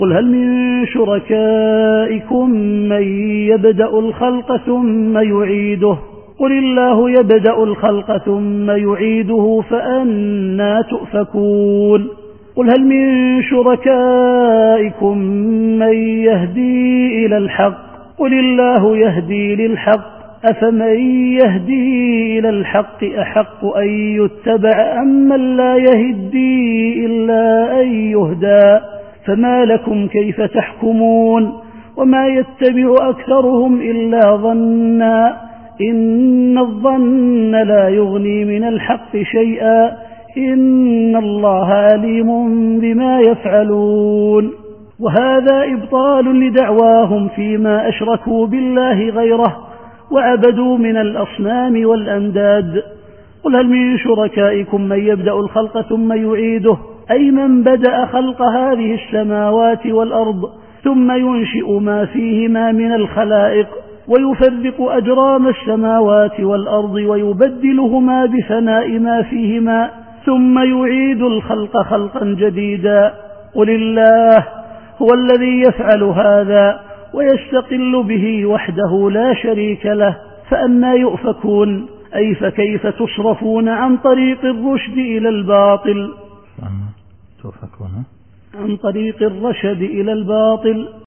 التفسير الصوتي [يونس / 34]